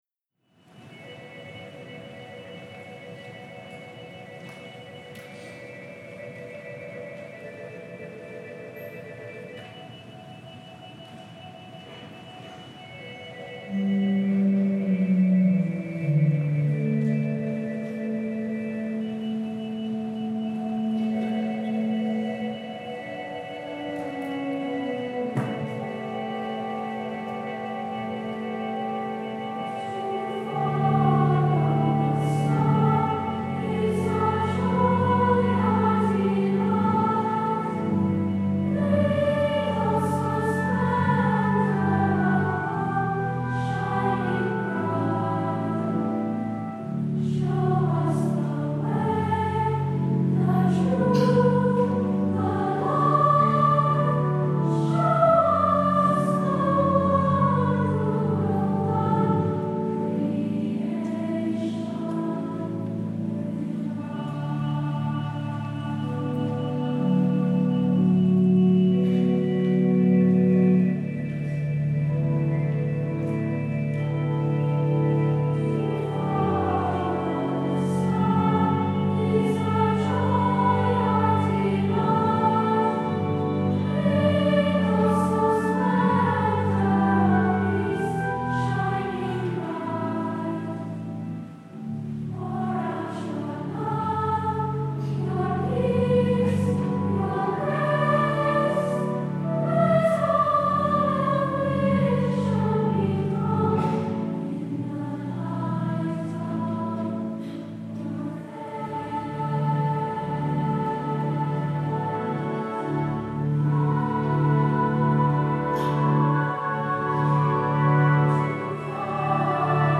Voicing: SSA and Organ